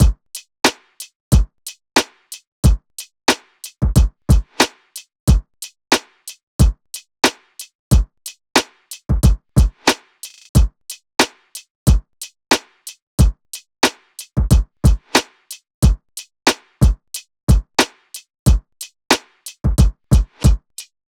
AV_Wealthy_Drums_91bpm
AV_Wealthy_Drums_91bpm.wav